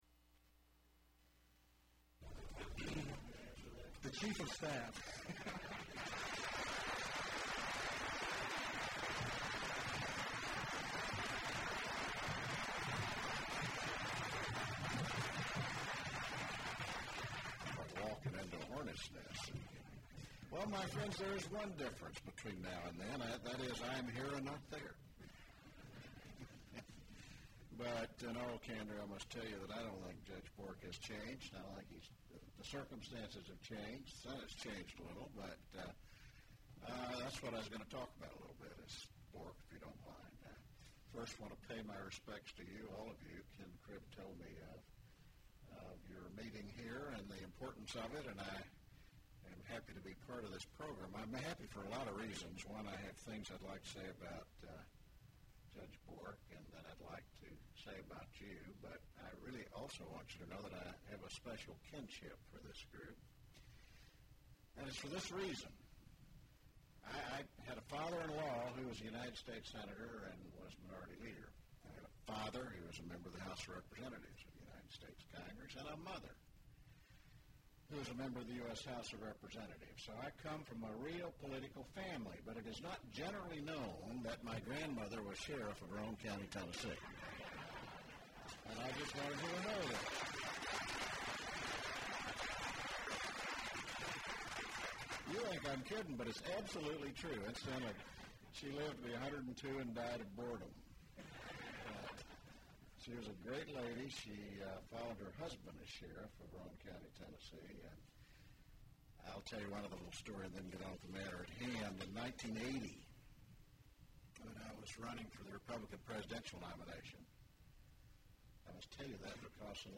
Remarks of Howard Baker and the President during drop by Briefing with Public Liaison for Public Bill of Rights